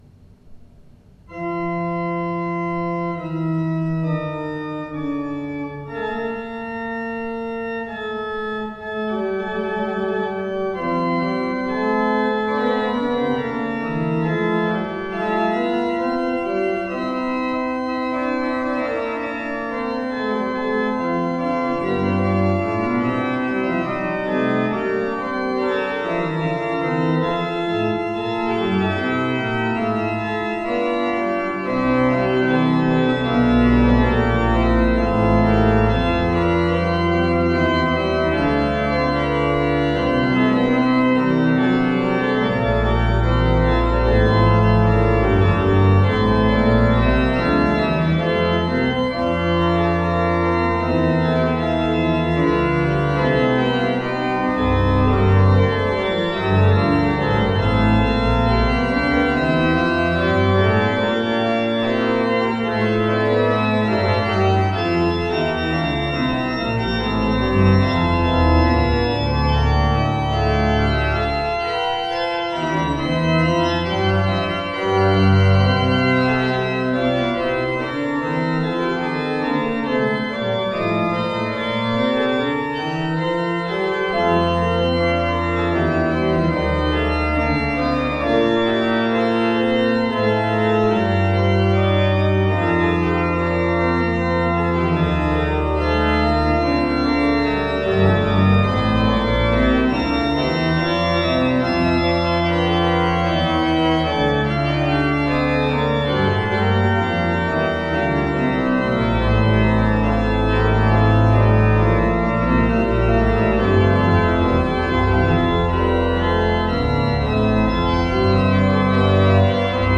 The sound files listed below are not live service recordings due to obvious logistic difficulties. The recordings are taken from rehearsal tapes made in the week prior to the service in question.